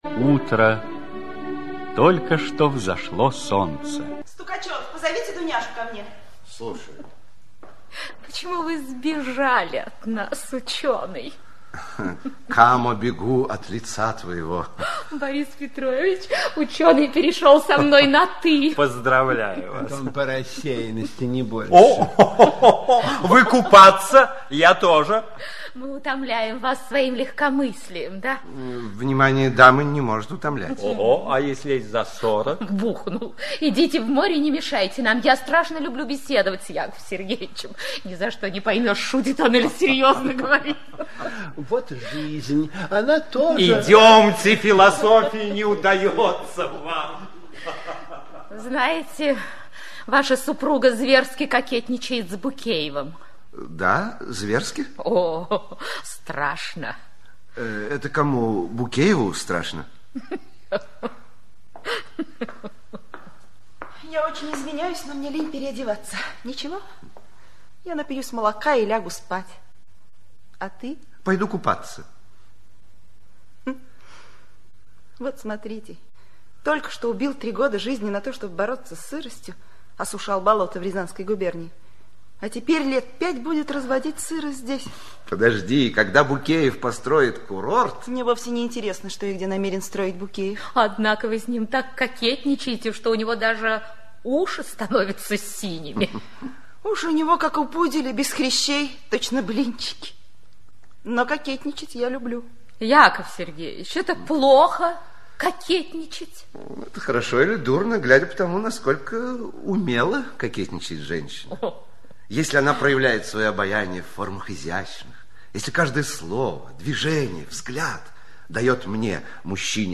Аудиокнига Яков Богомолов (спектакль) | Библиотека аудиокниг
Aудиокнига Яков Богомолов (спектакль) Автор Максим Горький Читает аудиокнигу Актерский коллектив.